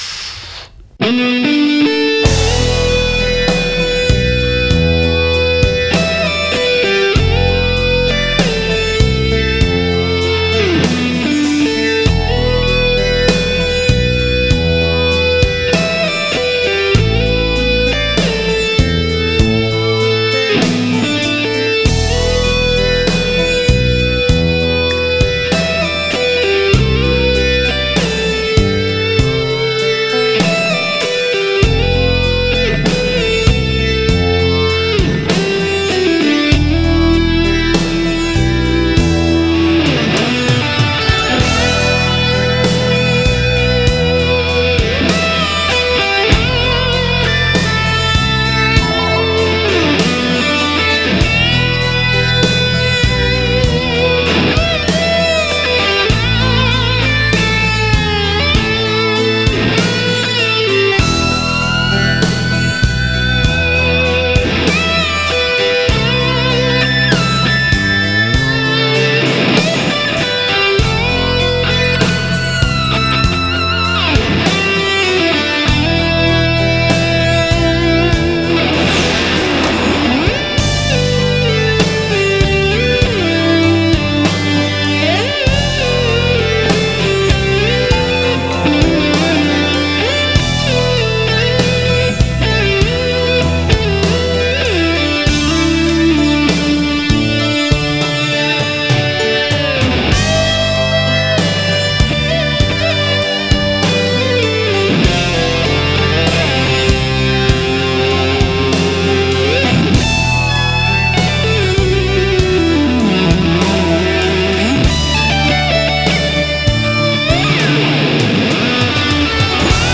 آهنگ راک